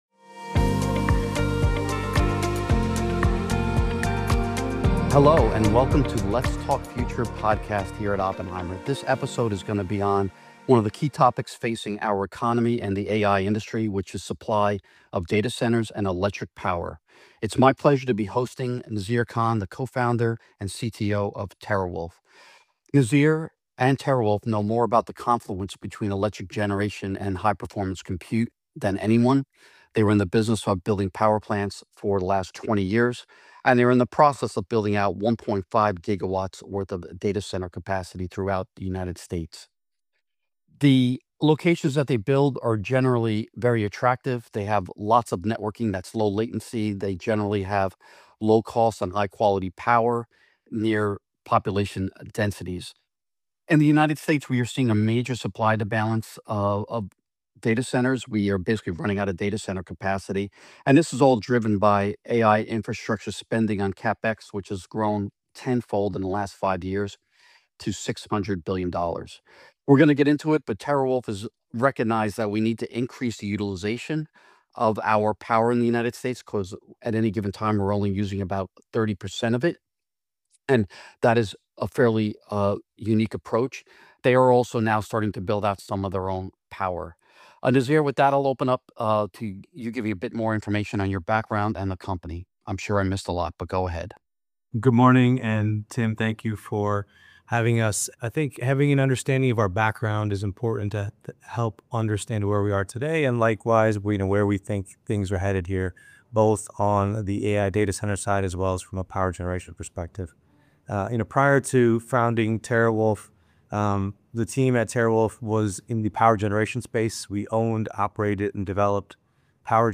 A Podcast Conversation